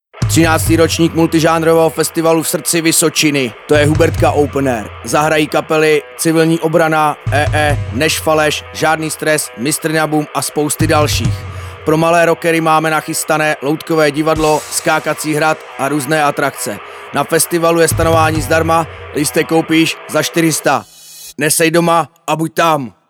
Radiospot-2024-Hubertka.mp3